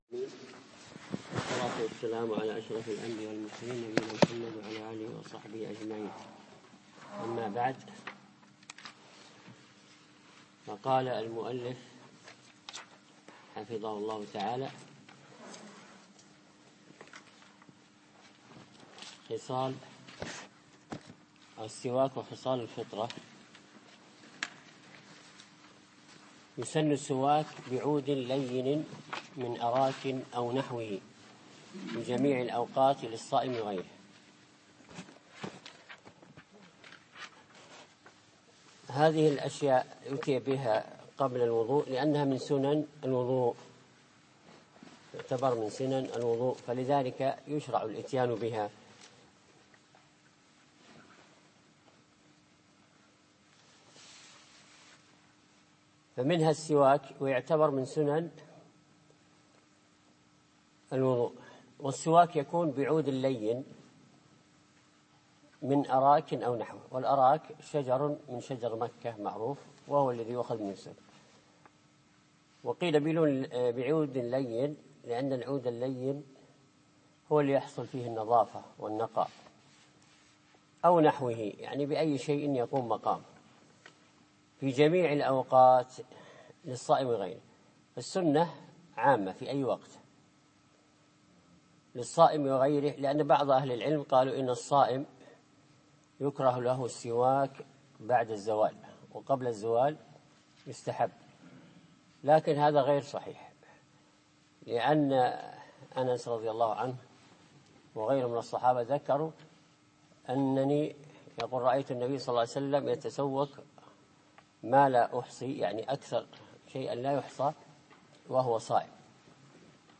أقيم الدرس فجر السبت 7 3 2015 في مسجد براك العواجي منطقة اشبيليا